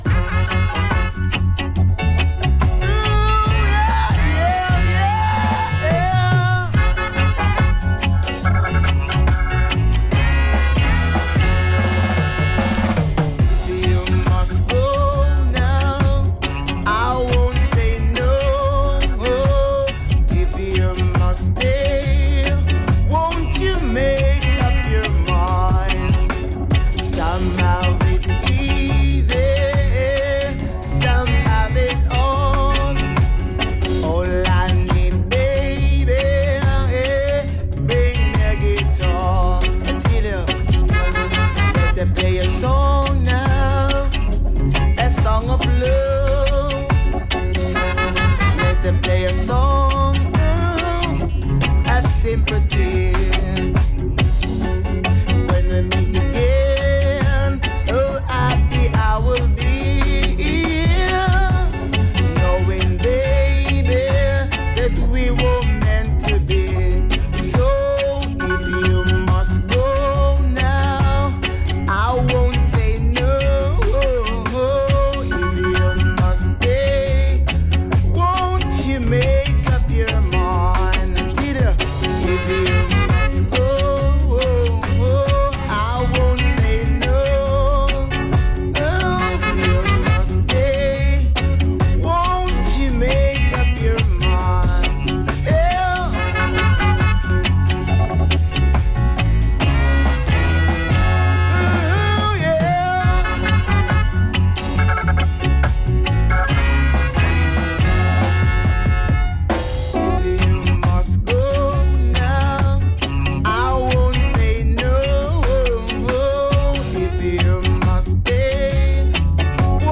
NOTES: Short show technical issues at the studio !!